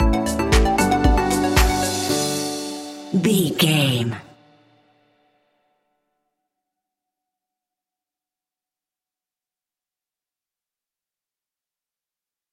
Ionian/Major
D
groovy
energetic
uplifting
futuristic
hypnotic
drum machine
synthesiser
house
electro house
funky house
instrumentals
synth leads
synth bass